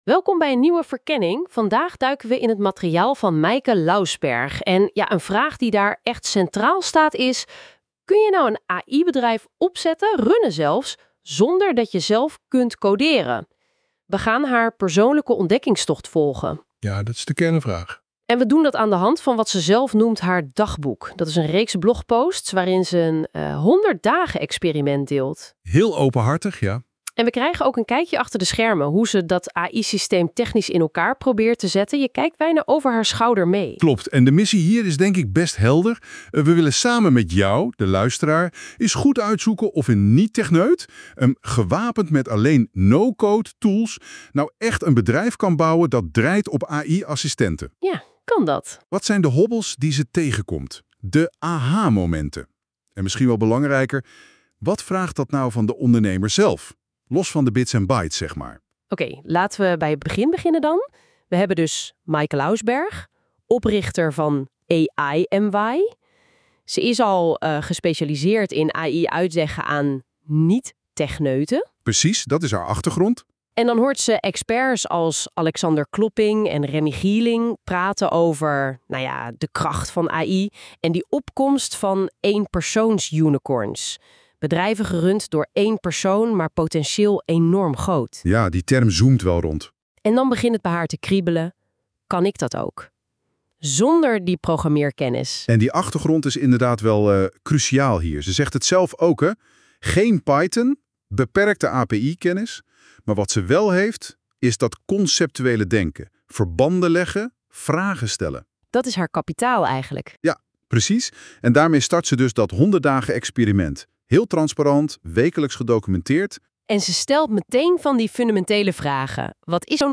Hij is (uiteraard) met AI gemaakt in NotebookLM, op basis van al mijn blogposts tot nu toe.